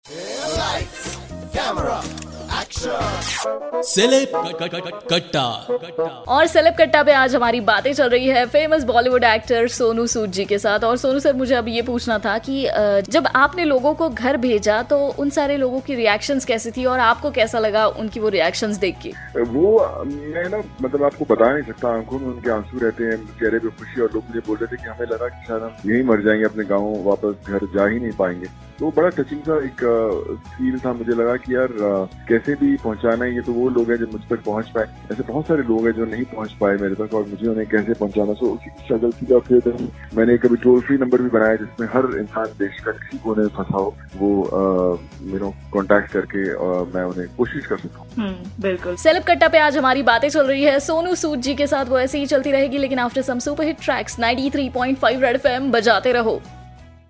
took an interview of famous Actor Sonu Sood..In this interview Sonu sood shared reactions of people whom he send it there hometown..